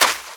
STEPS Sand, Run 28.wav